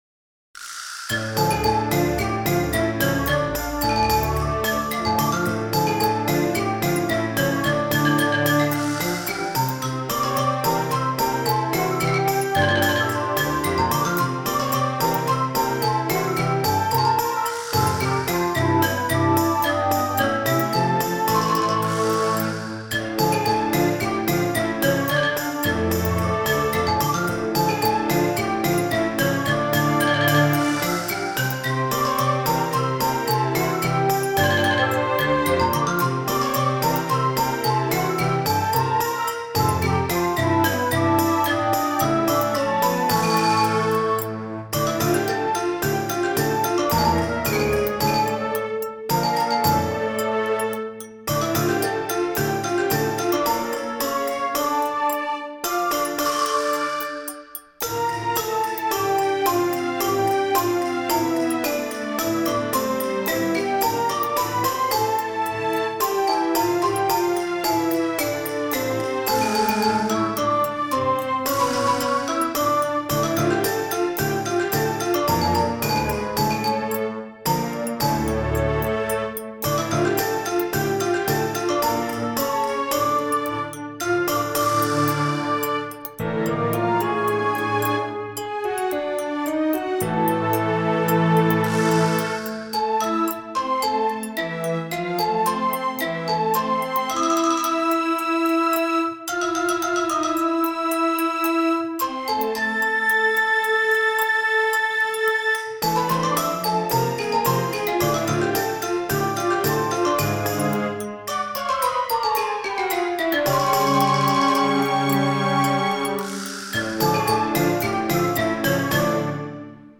ハロウィン曲のつもりのなんちゃってオーケストラ曲。